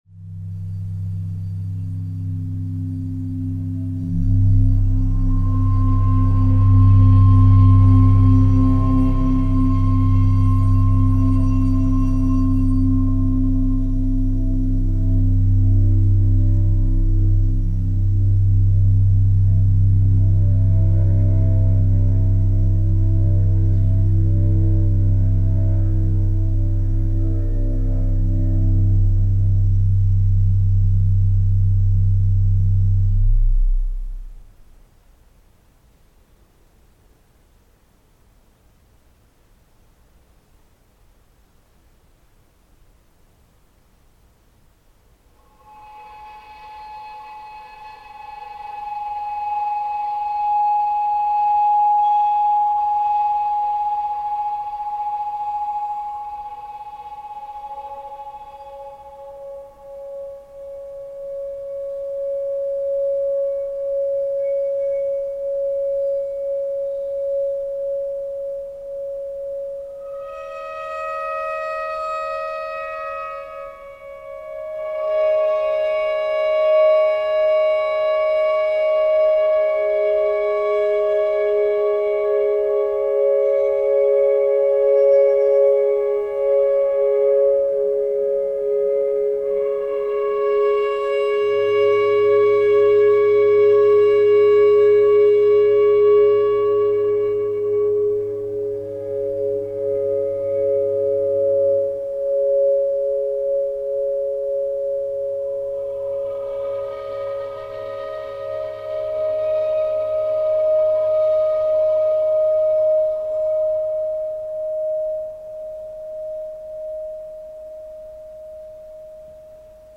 持続音マニアにも激激オススメしたい2枚組作！！
録音会場となっている礼拝堂の空間そのものと同化している様な、もはや気配や空気の様な領域にまで踏み込んだ激ヤバな全編。
Three detailed and fragile compositions
performed by the Boston-based experimental music ensemble
violin
cello
vibraphone/piano
guitar
organ